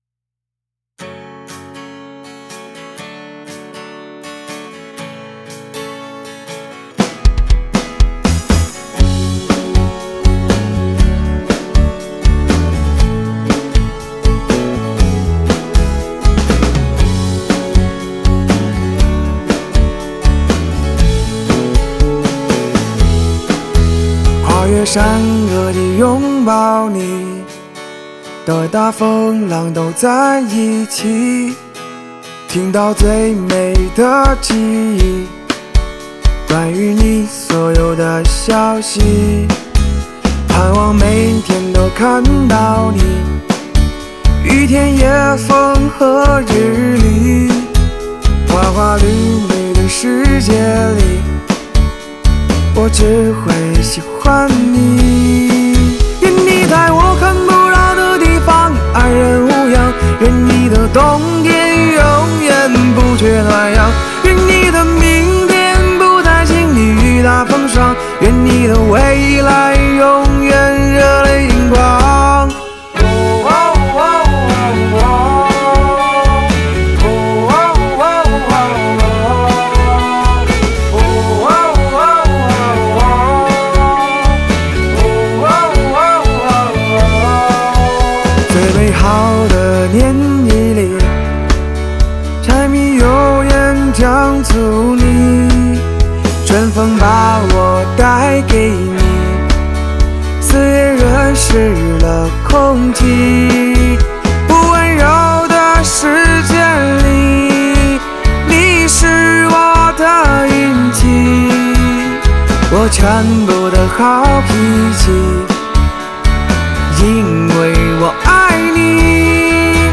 Ps：在线试听为压缩音质节选，体验无损音质请下载完整版
吉他
和声